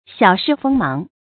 小試鋒芒 注音： ㄒㄧㄠˇ ㄕㄧˋ ㄈㄥ ㄇㄤˊ 讀音讀法： 意思解釋： 比喻稍微顯示一下本領。